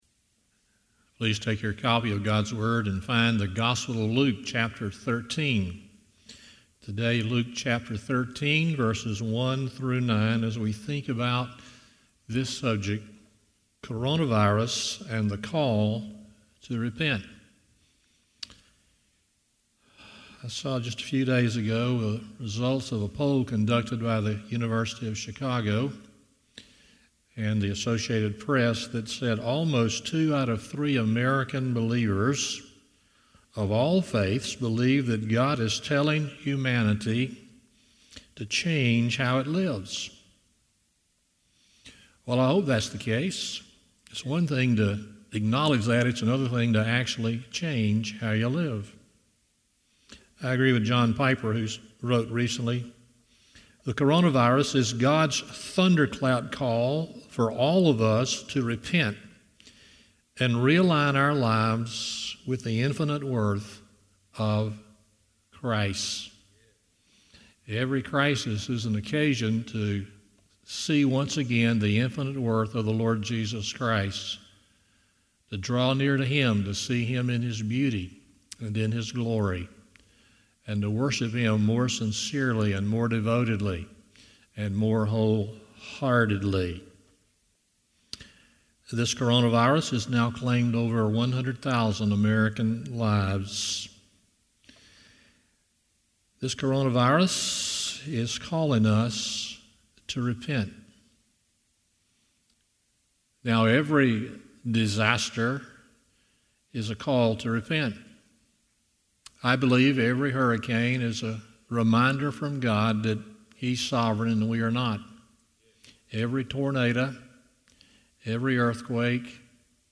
Luke 13:1-9 Service Type: Sunday Morning 1.